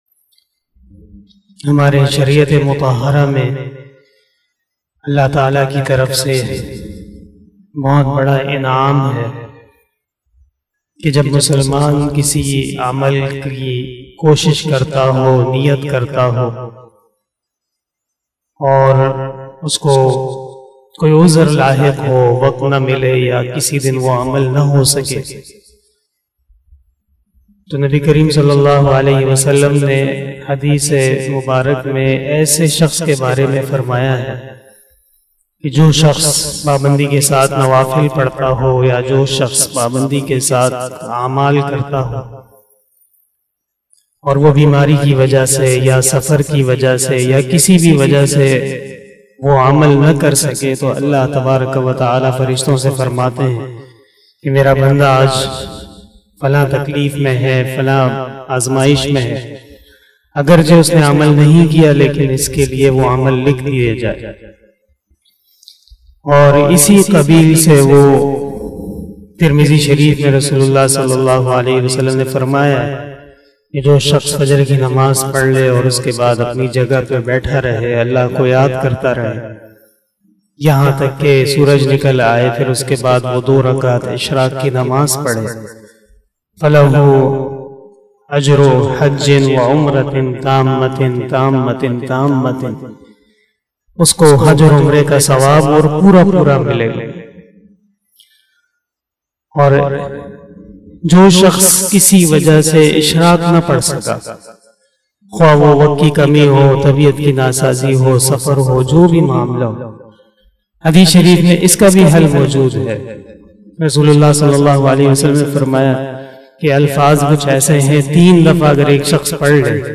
042 After Fajar Namaz Bayan 11 August 2021 (02 Muharram 1443HJ) Wednesday
بیان بعد نماز فجر